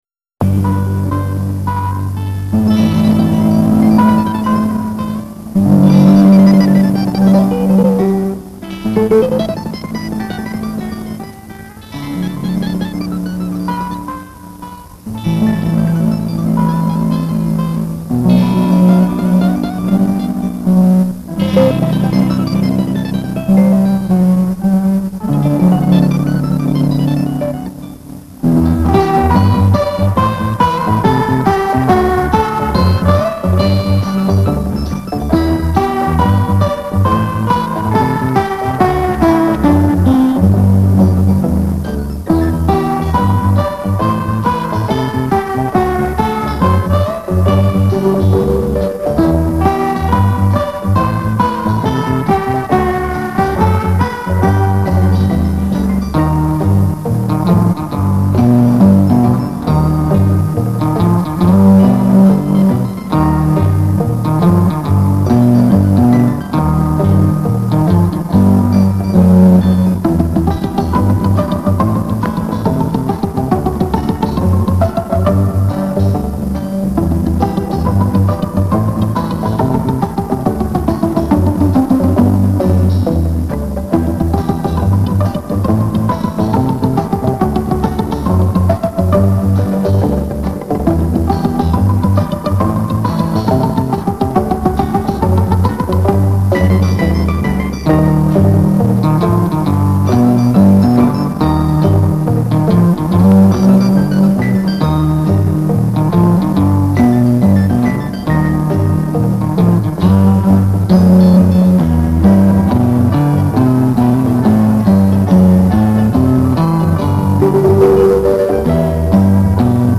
Записи со старых катушек.
Прошу опознать инструментал.